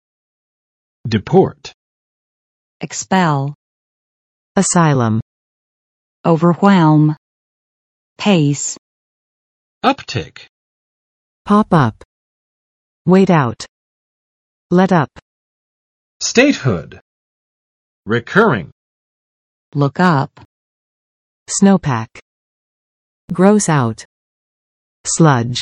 [dɪˋport] v. 驱逐（出境）；放逐